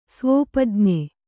♪ svōpajñe